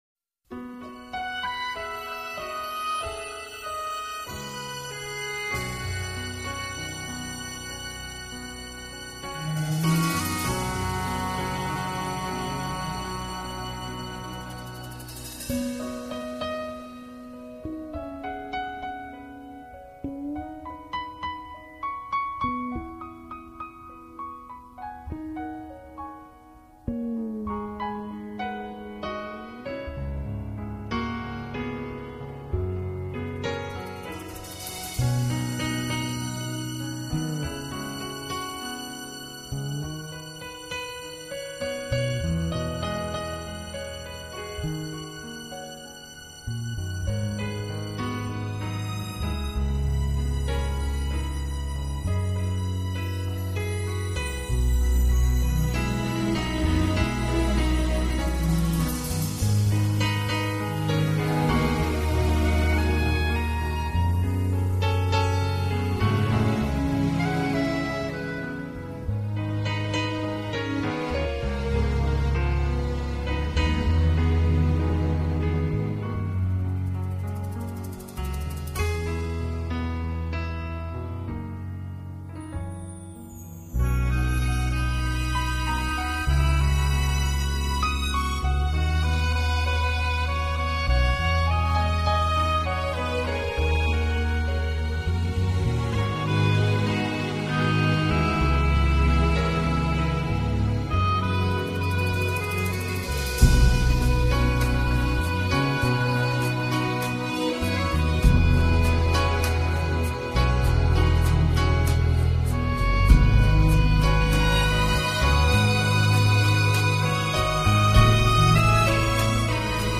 音乐风格：New Age
在钢琴、小提琴、长笛、双簧管等乐器交融配合演绎下，展现出一首首优美动听，风采各异的音乐，值得细细品尝。